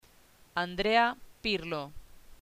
Andrea PIRLOAndréa Pírlo